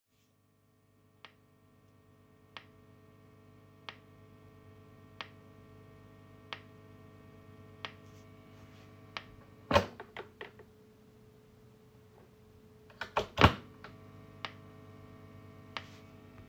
Boss Katana 100 MKII knackt Hi zusammen, ich habe ein merkwürdiges immer gleichbleibendes Knacken bei meinem Katana. Wenn ich Das Kabel einstecke knackt es, wenn ich es rausziehe dann nicht mehr.
Hier mal eine Tonaufnahme (zum Schluss zieh ich das Kabel und steck es wieder rein).